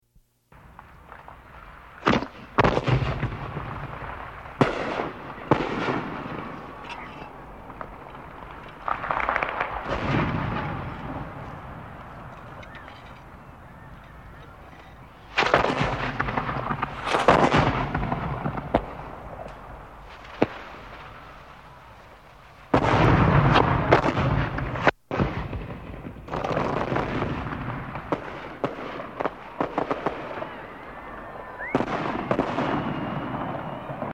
دانلود آهنگ سه بعدی 4 از افکت صوتی طبیعت و محیط
جلوه های صوتی